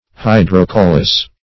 Search Result for " hydrocaulus" : The Collaborative International Dictionary of English v.0.48: Hydrocaulus \Hy`dro*cau"lus\, n.; pl. Hydrocauli .
hydrocaulus.mp3